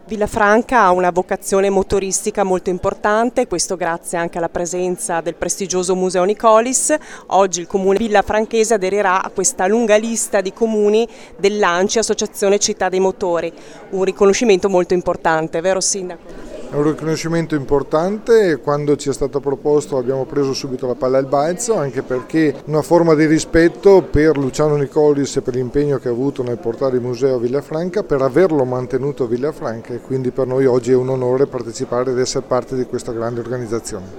ha intervistato per noi:
il sindaco di Villafranca, Roberto Dall’Oca